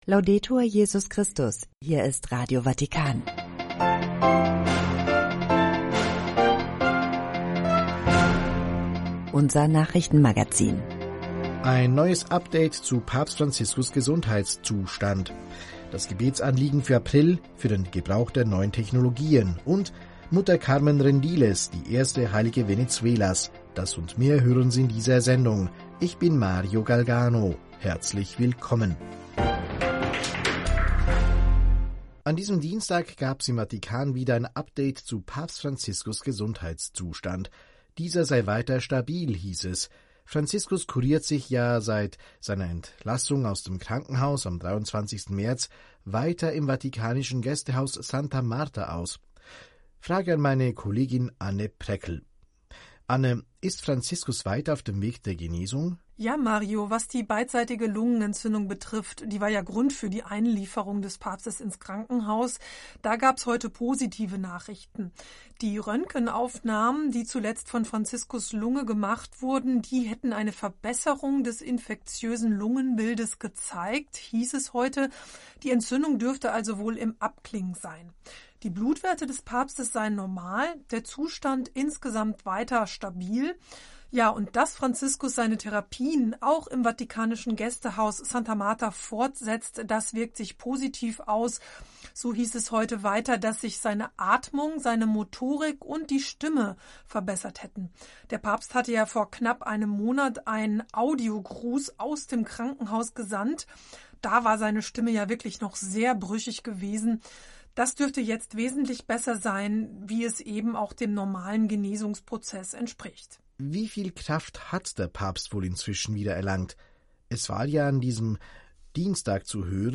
… continue reading 2 つのエピソード # vatican city # Nachrichten # Radio Vaticana Vatican News